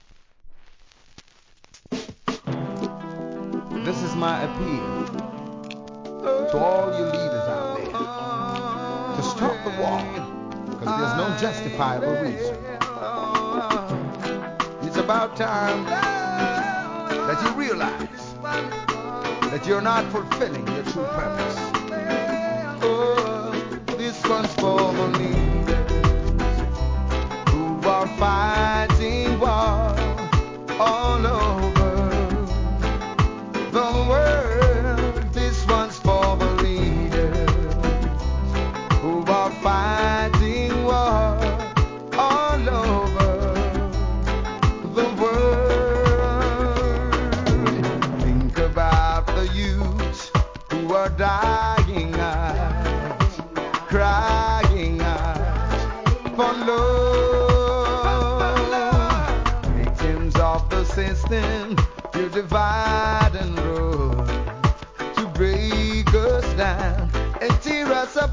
REGGAE
ミディアムの超BIG HITリディム!!